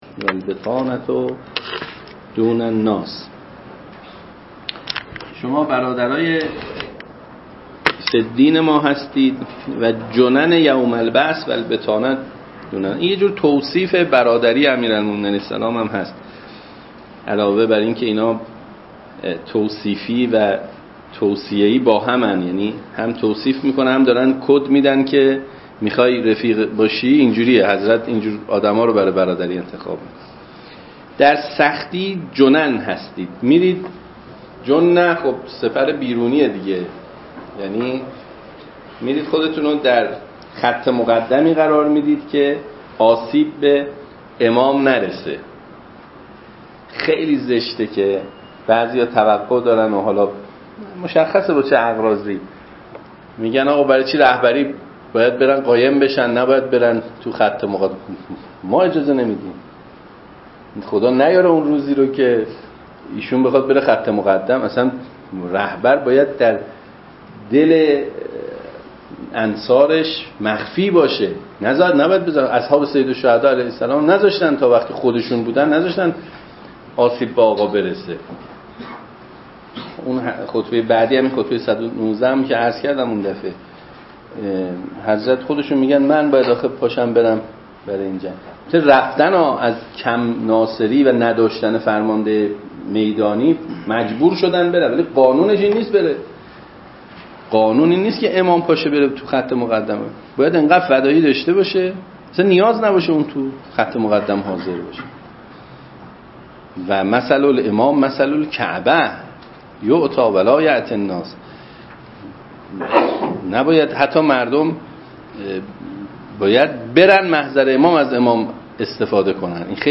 درس الاخلاق